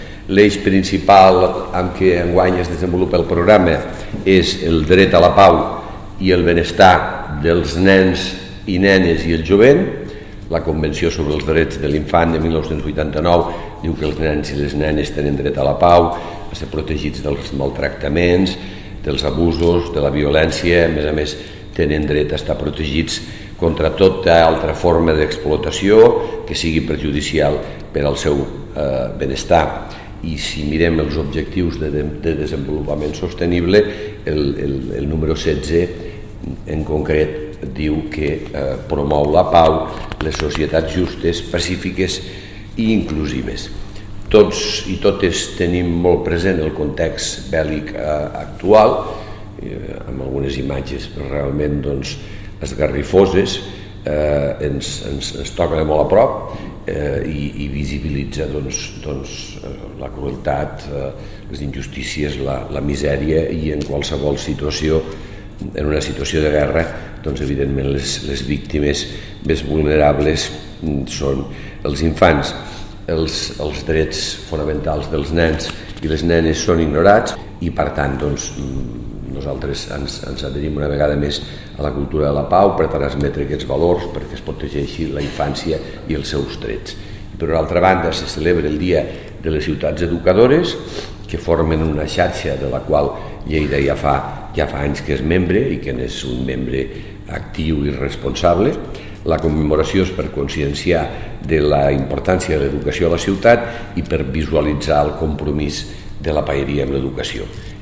tall-de-veu-del-paer-en-cap-miquel-pueyo-sobre-el-programa-dactivitats-entorn-dels-drets-dels-infants